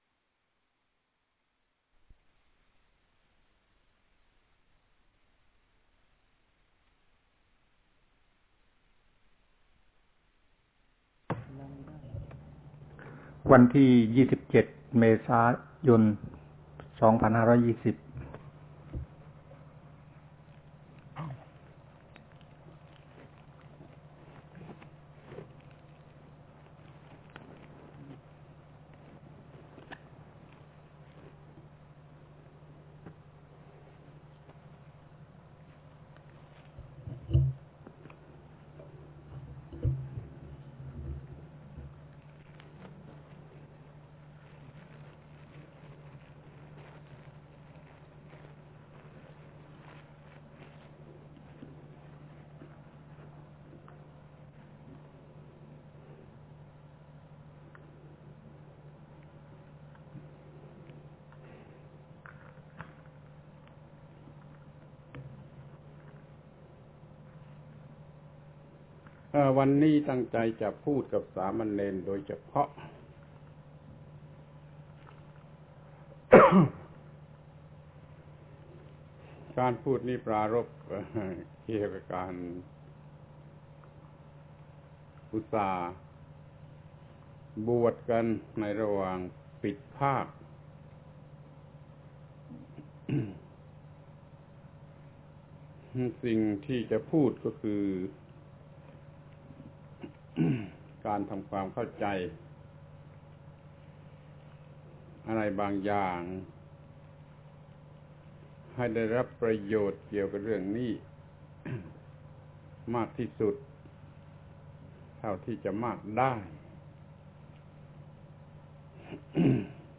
ฟังธรรมะ Podcasts กับ พระธรรมโกศาจารย์ (พุทธทาสภิกขุ)